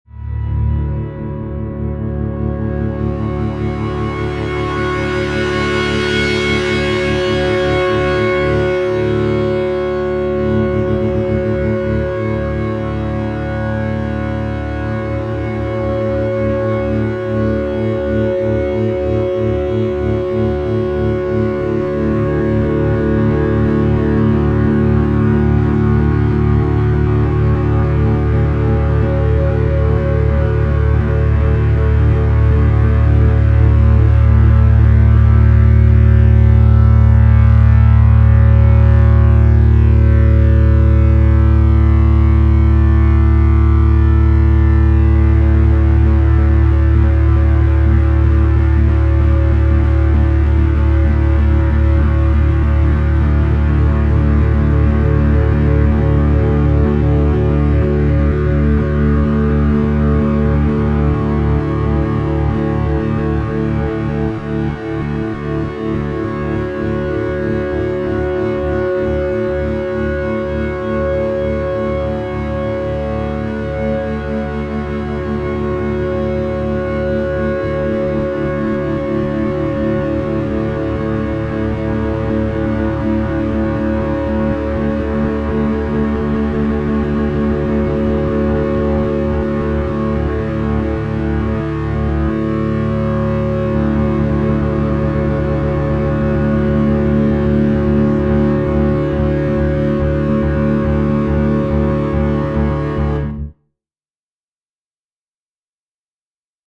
metal sync dronezzz